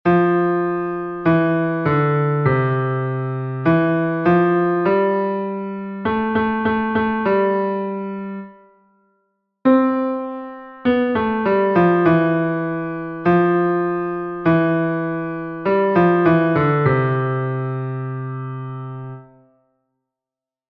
Here, there are two exercises in a 4/4 time signature and one exercise in a 2/4 time signature.